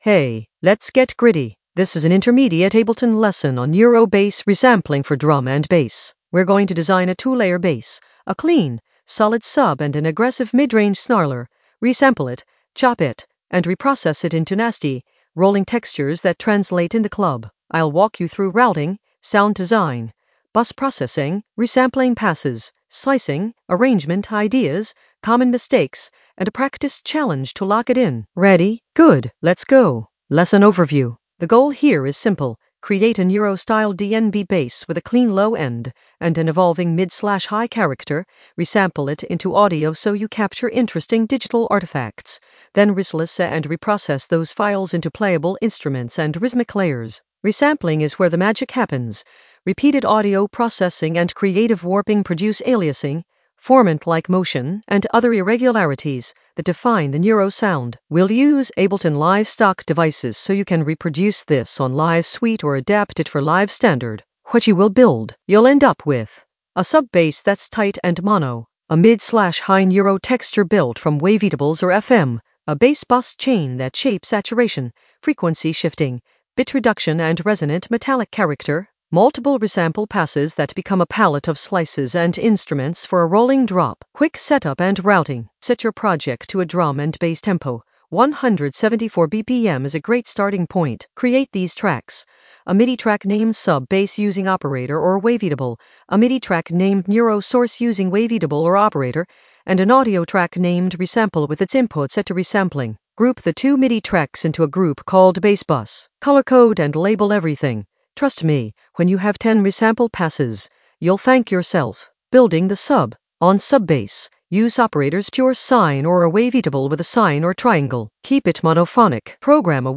Narrated lesson audio
The voice track includes the tutorial plus extra teacher commentary.
An AI-generated intermediate Ableton lesson focused on Neuro bass resampling in Ableton in the Sound Design area of drum and bass production.